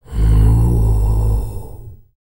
TUVANGROAN08.wav